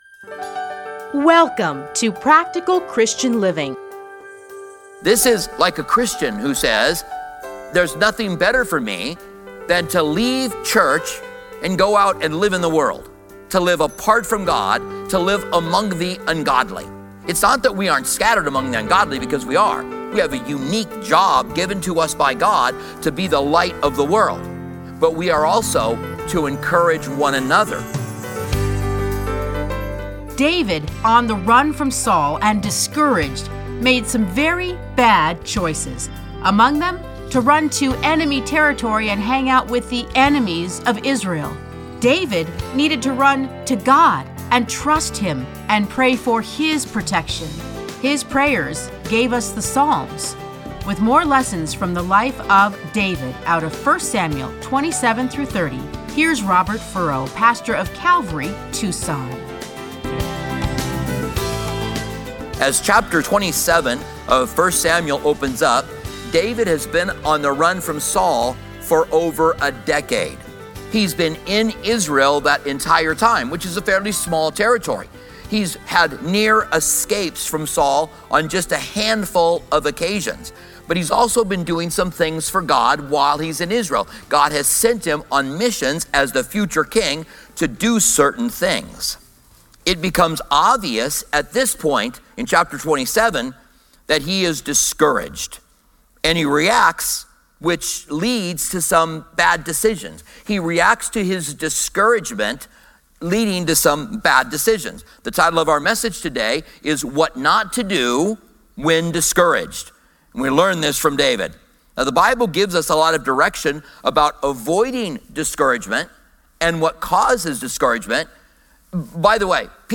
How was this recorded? teachings are edited into 30-minute radio programs titled Practical Christian Living. Listen to a teaching from 1 Samuel 27-30.